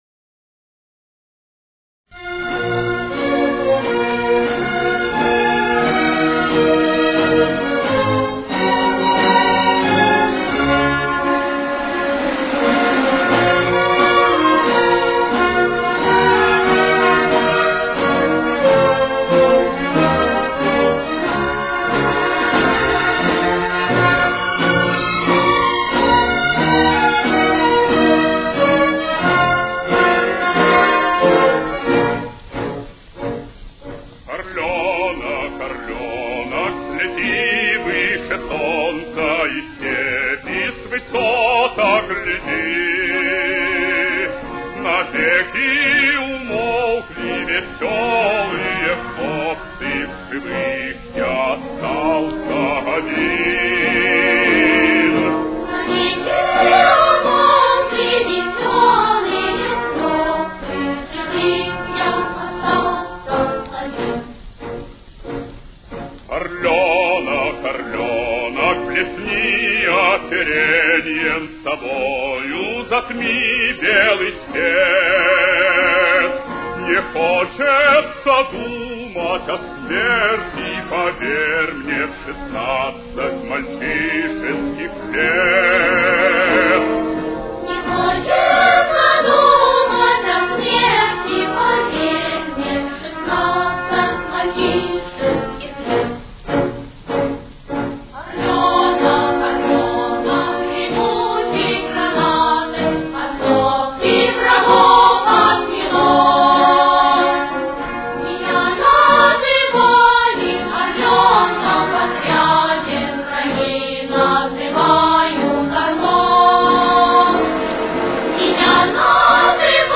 с очень низким качеством (16 – 32 кБит/с)
Си-бемоль минор. Темп: 91.